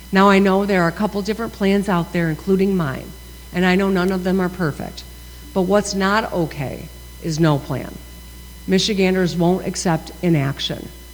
She delivered her 7th State of the State Address Wednesday night before a joint session of the Michigan House and Senate.